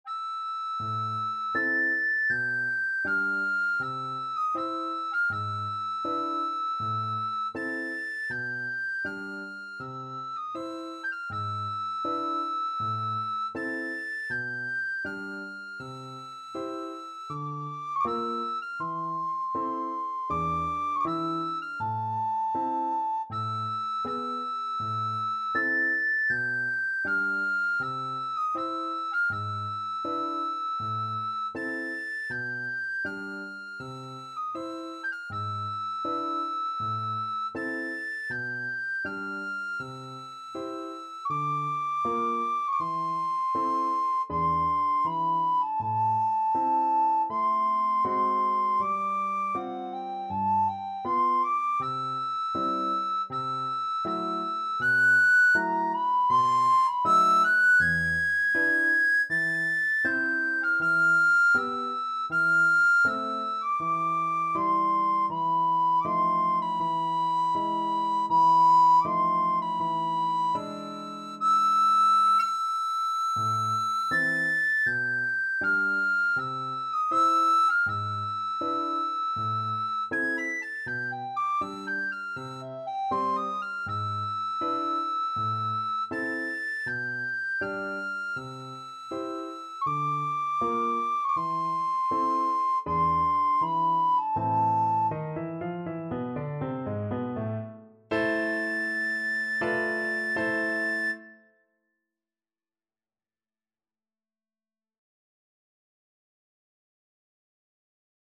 Free Sheet music for Soprano (Descant) Recorder
Andante = c.80
4/4 (View more 4/4 Music)
A minor (Sounding Pitch) (View more A minor Music for Recorder )
Classical (View more Classical Recorder Music)